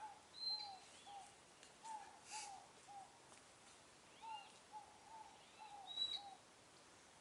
7. Rough-Legged Hawk (Buteo lagopus)
• Call: Soft mewing: